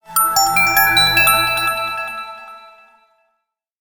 06741 good news magic ding
announce ding effect electronic intro jingle magic news sound effect free sound royalty free Sound Effects